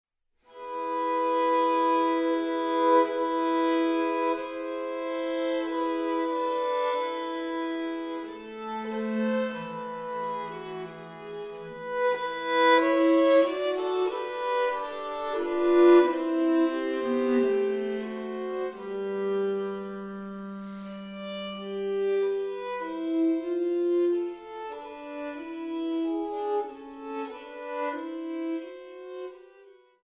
Laute
Viola da Gamba
Flöte/Dulzian
Violine/Viola